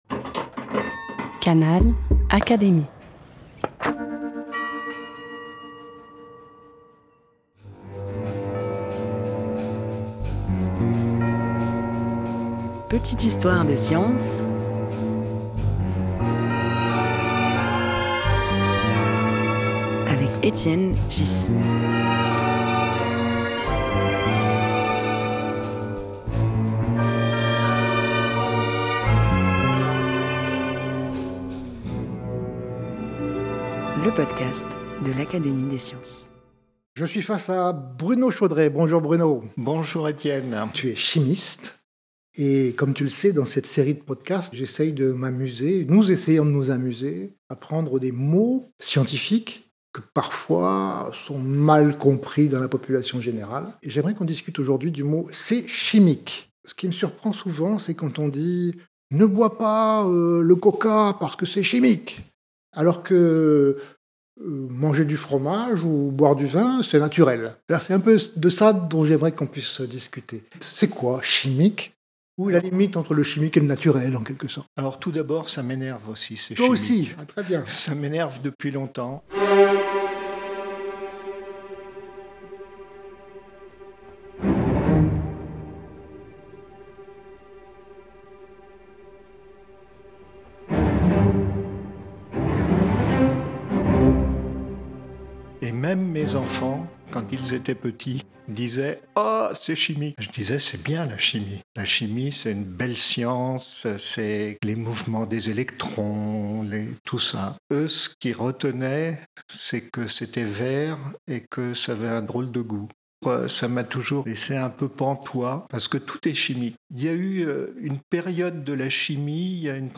Un podcast animé par Étienne Ghys, proposé par l'Académie des sciences.